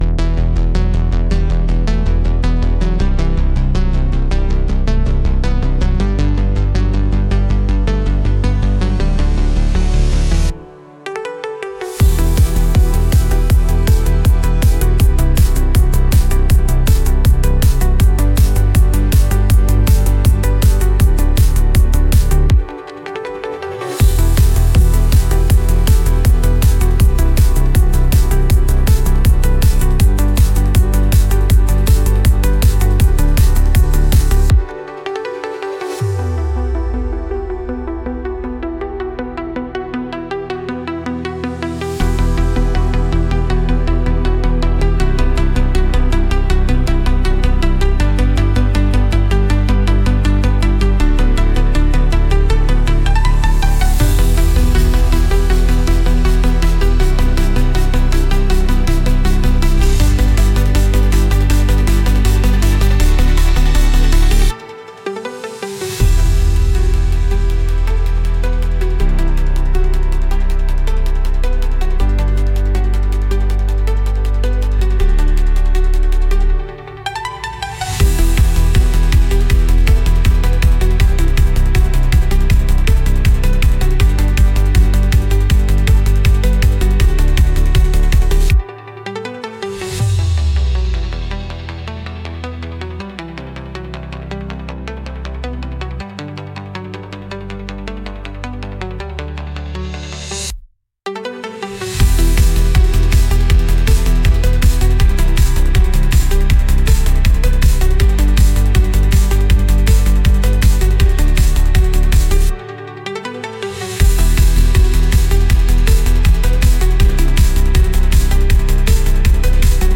Instrumental -Real Liberty Media DOT xyz- 2.59